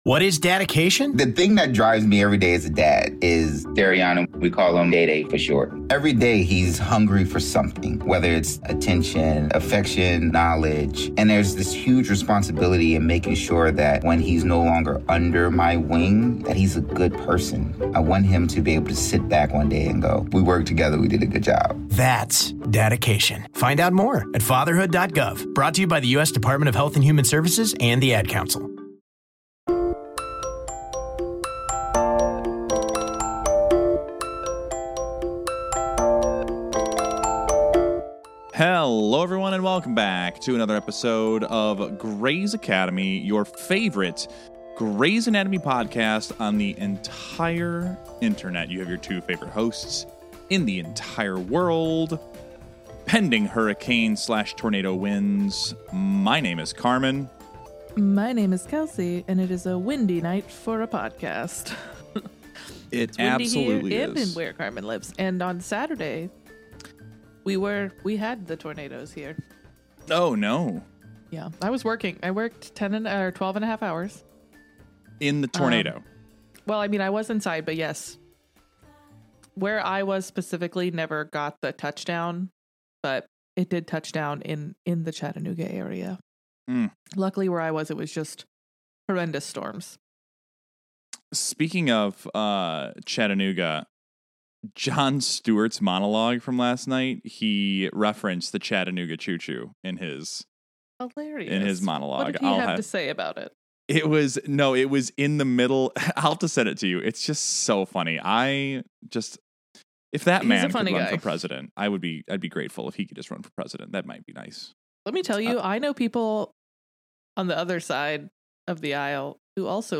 <3 For real, this episode is hard for Cristina and Owen. Listen in to all the live reactions!